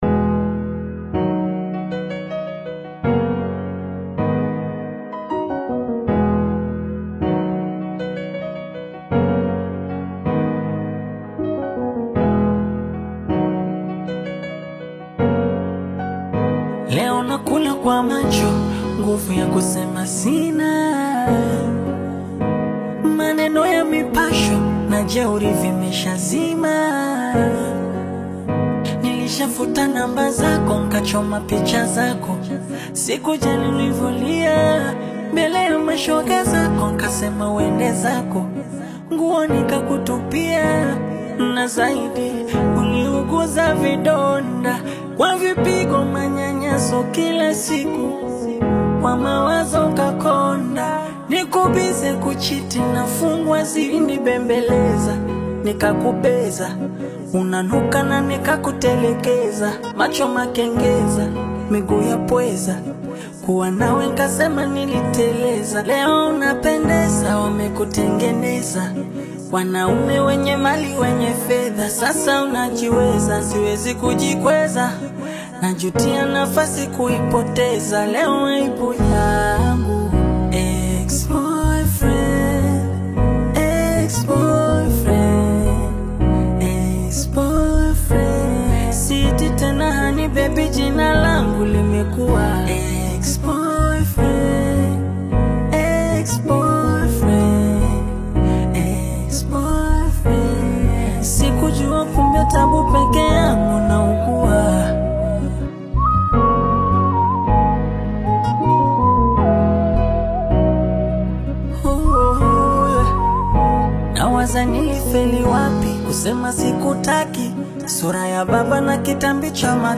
African Music You may also like